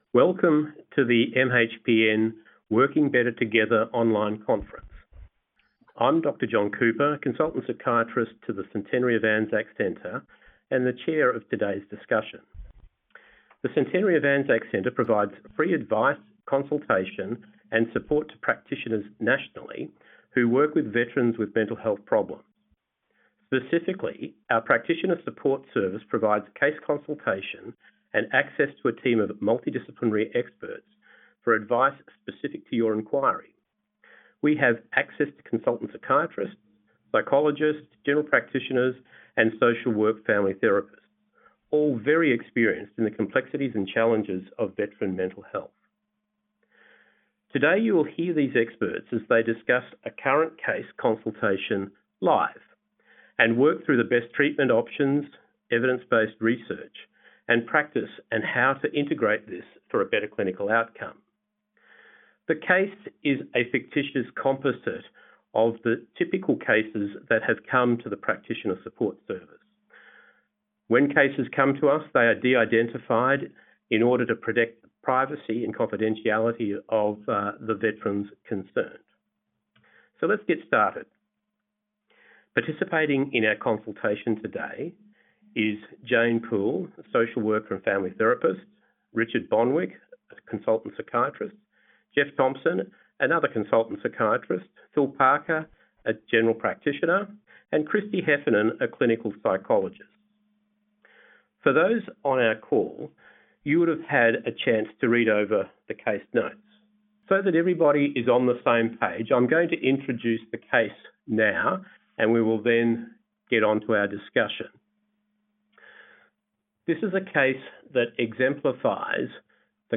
Listen in on this discussion between interdisciplinary experts for a about helping a welfare worker to support a client. Presented by the Centenary of Anzac Centre, this webinar is part of the Working Better Together Online Conference 2019 hosted by the Mental Health Professionals' Network (MHPN).